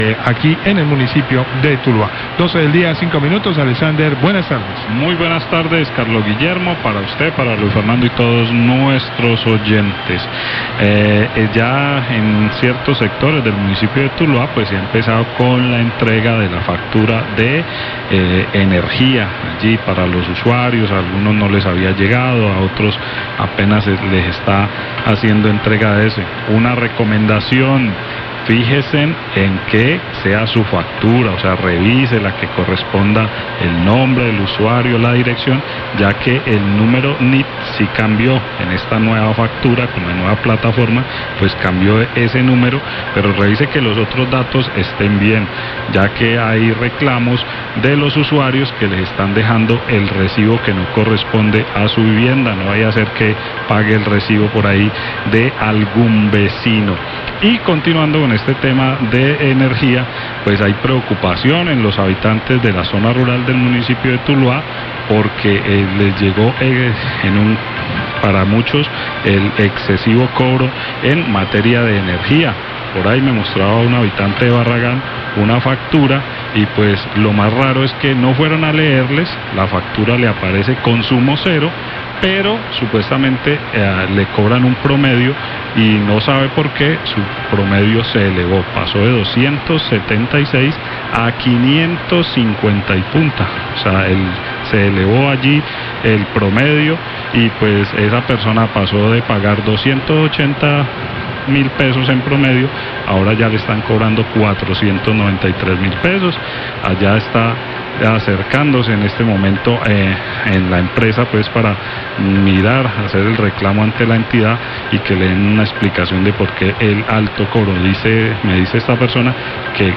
Oyente de la zona rural manifestó su molestía por el cobro por promedio del servicio de energía, La Cariñosa 1207pm
Radio